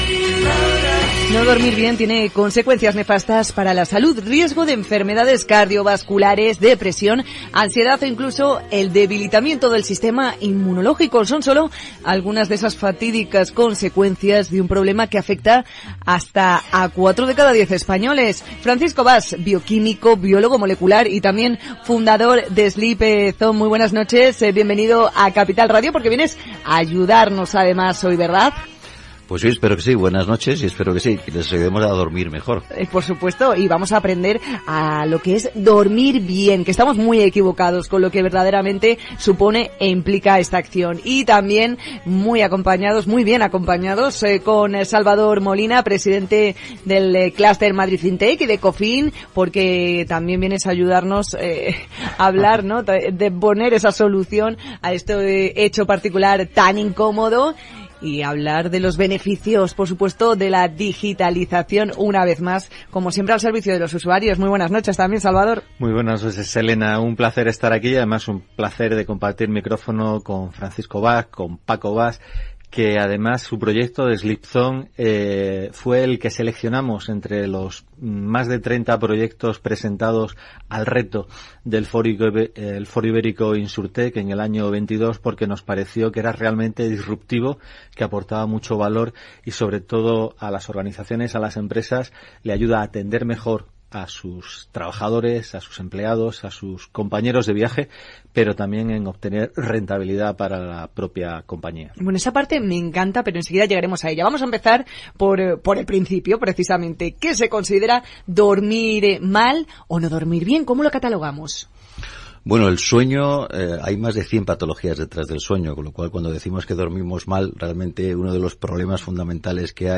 CapitalRadioSleepzZone2023.mp3